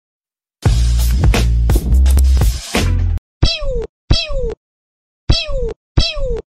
Meme sound có thể bạn sound effects free download